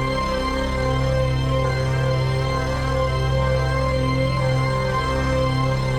Index of /musicradar/dystopian-drone-samples/Non Tempo Loops
DD_LoopDrone2-B.wav